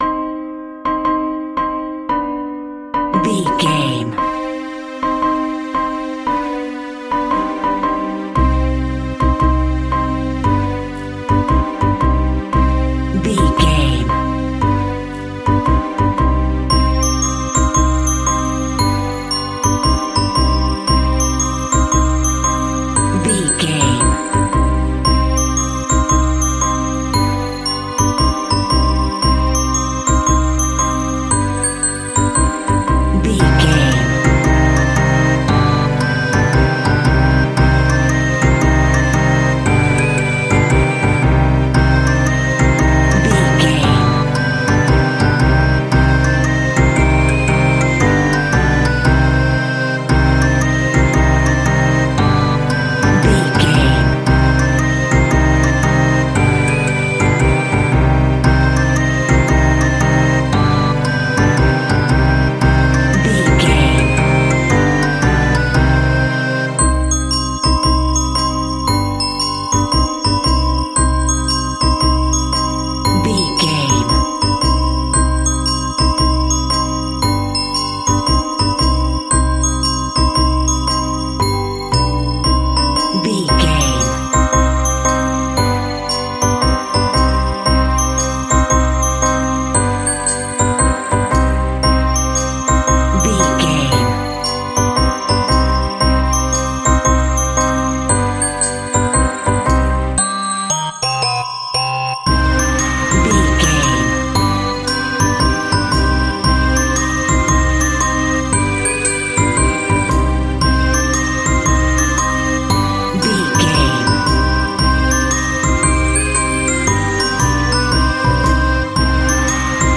Scary and Mysterious Computer Game.
Aeolian/Minor
tension
ominous
eerie
piano
synthesiser
percussion
strings
creepy
instrumentals
horror music